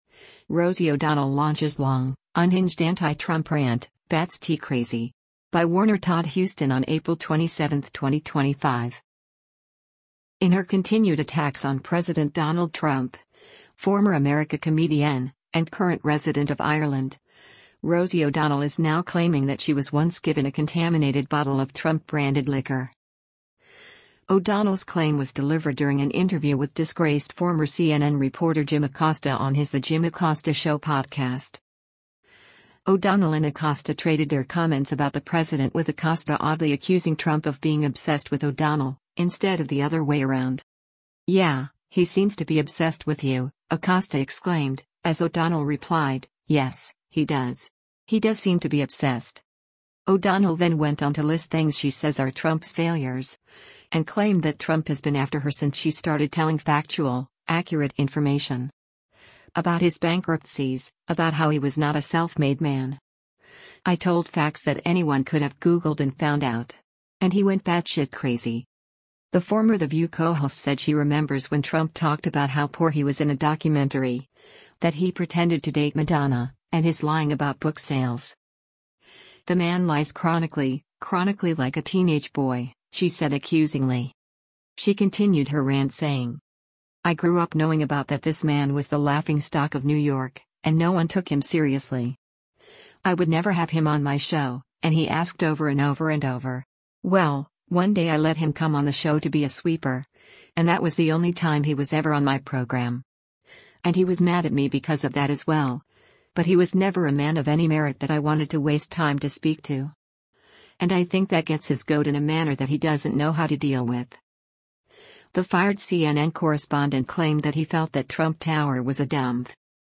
O’Donnell’s claim was delivered during an interview with disgraced former CNN reporter Jim Acosta on his The Jim Acosta Show podcast.